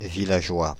Prononciation
Prononciation France: IPA: [vi.la.ʒwa] Paris Le mot recherché trouvé avec ces langues de source: français Traduction 1.